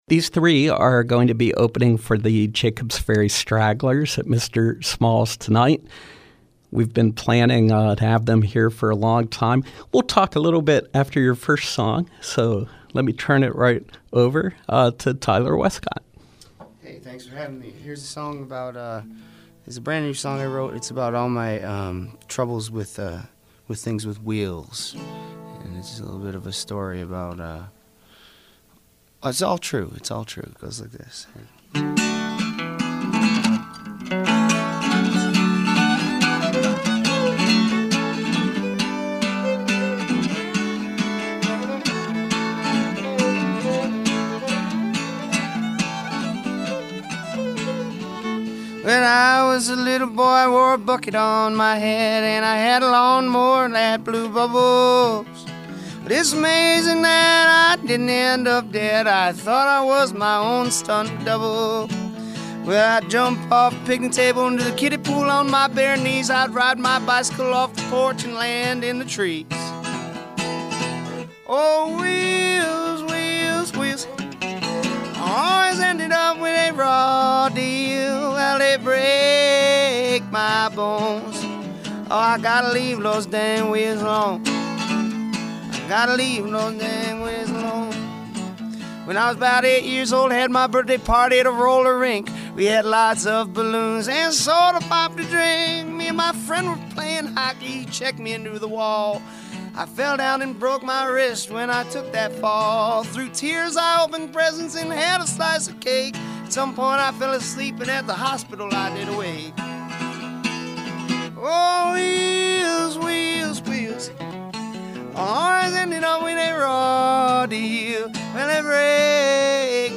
fiddle
ukulele